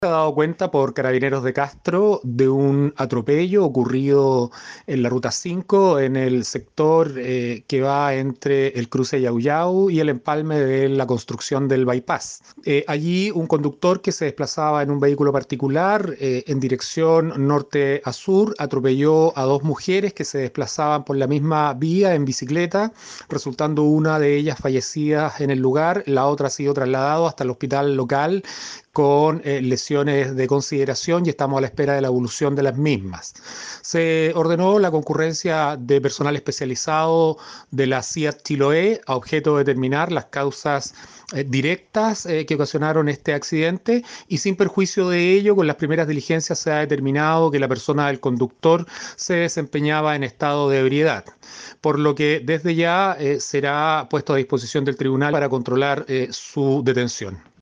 Acerca de esta tragedia, el fiscal Enrique Canales se refirió al procedimiento por el atropello de estas dos mujeres por parte de sujeto que conducía en estado de ebriedad, según se estableció.